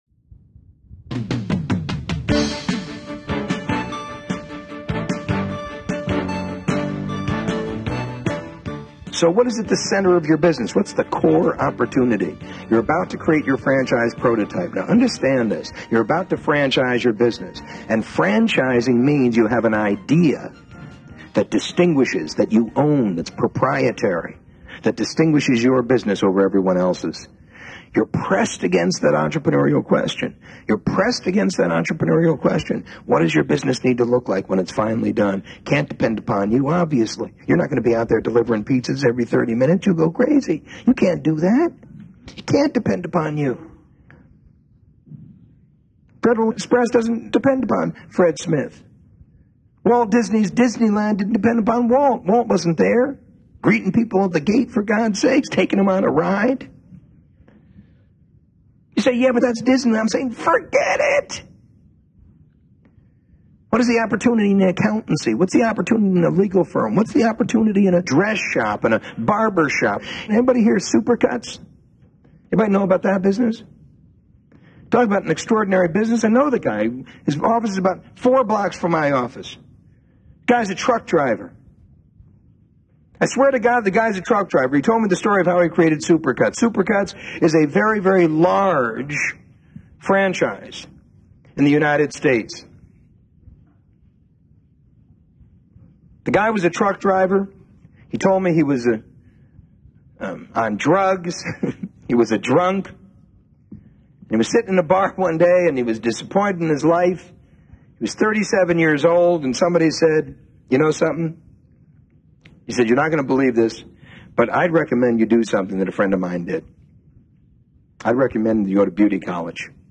This audio is from the EMyth Seminar Nightingale Conant tape set.
EMyth Seminar Tape 4 of 6